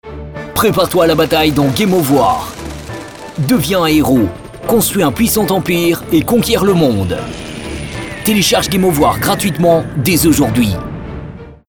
Sprechprobe: Werbung (Muttersprache):
Stunning medium voice. Young and dynamic